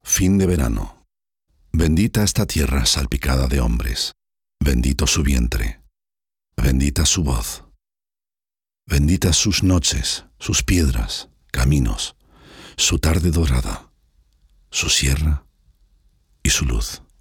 Zwischen Spanien und Deutschland zweisprachig hin und her pendelnd lernte er 2 Sprachen akzentfrei zu sprechen.
Sprechprobe: Sonstiges (Muttersprache):